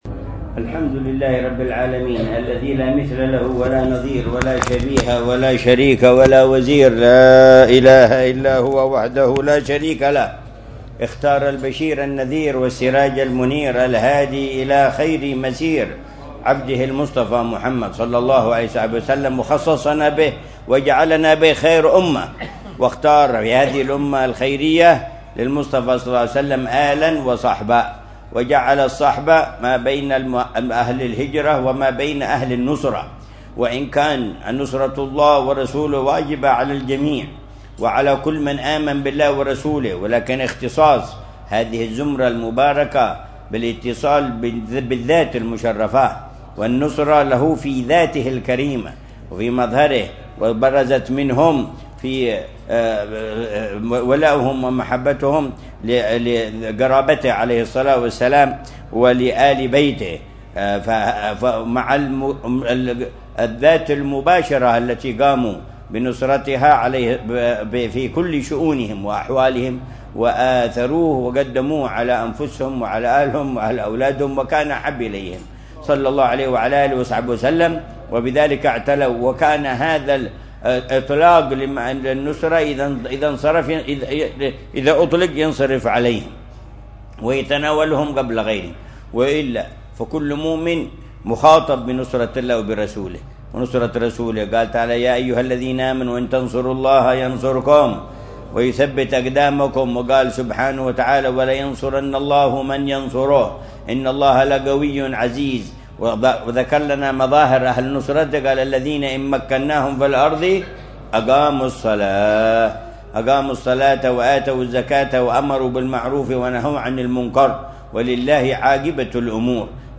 مذاكرة العلامة الحبيب عمر بن محمدبن حفيظ في مسجد الوعل، في حارة الخليف، مدينة تريم، ليلة السبت 11 رجب الأصب 1446هـ بعنوان: مجالي النصرة لله ورسوله ووجوبها وخصائص الأنصار الأولين